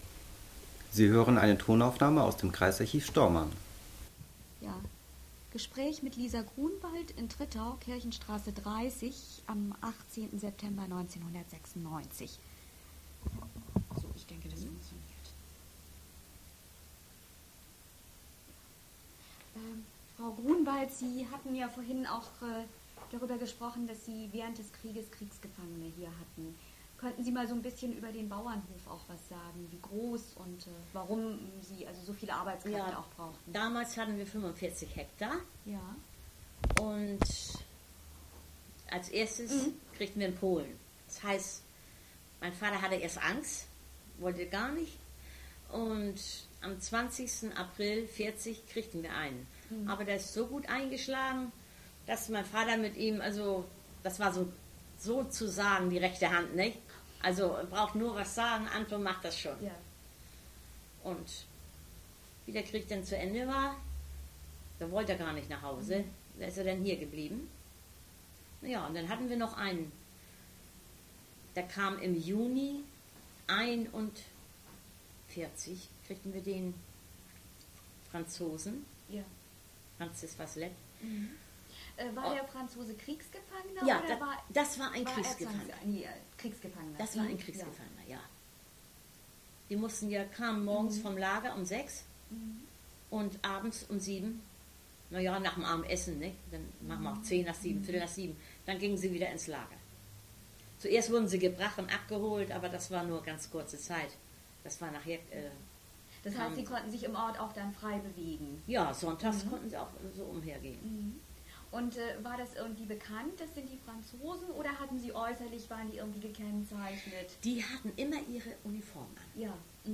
Tonkassette